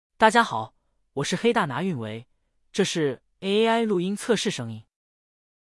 AI配音神器，热门声音模型助力创作！
各种声音模型都可以用，并且可以调节倍速下载，下面是我随即挑选的几款声音预览：